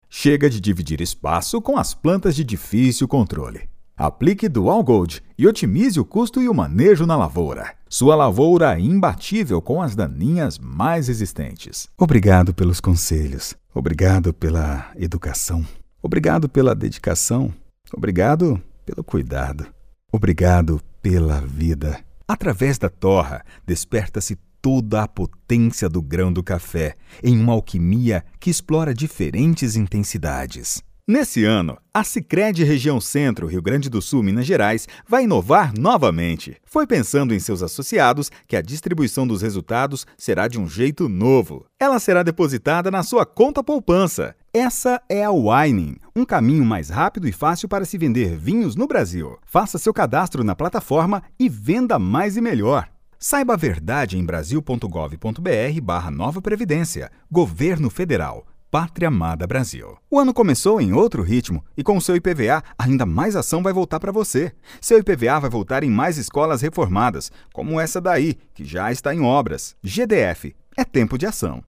专题解说【大气稳健】